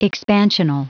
Prononciation du mot expansional en anglais (fichier audio)
Prononciation du mot : expansional